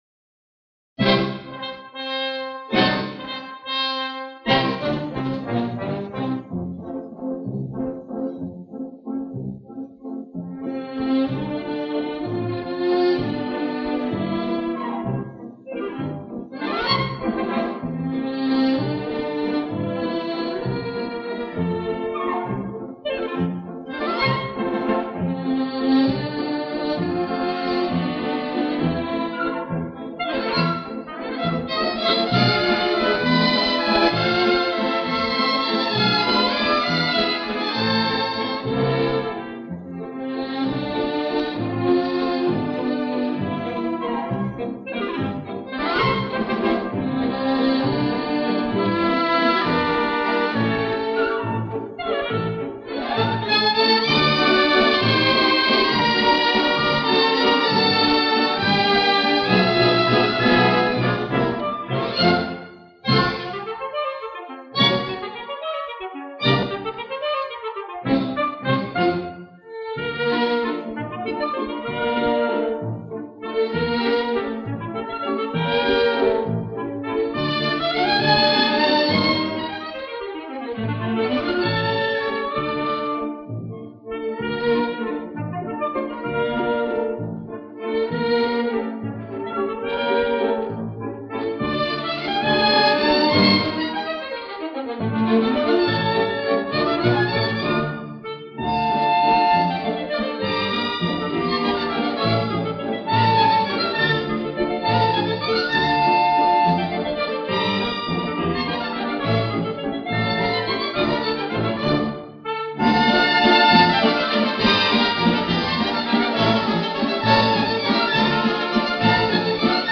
Оркестр (какой - не написано)